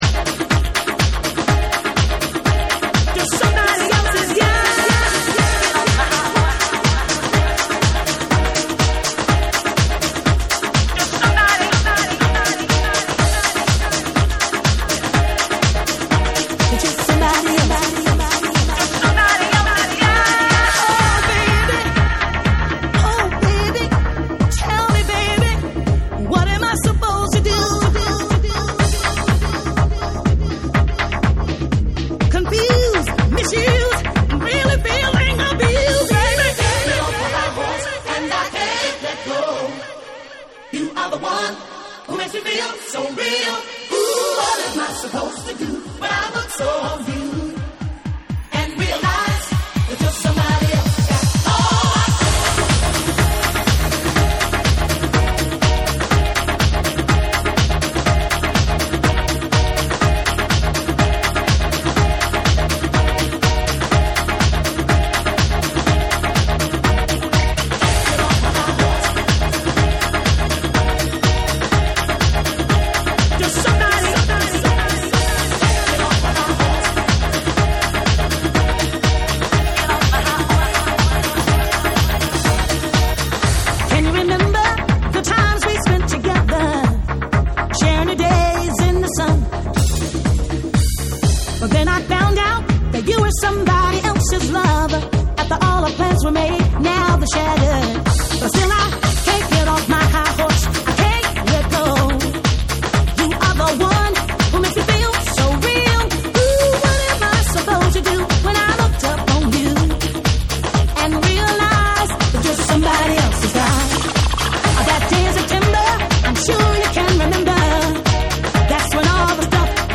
DANCE CLASSICS / DISCO / TECHNO & HOUSE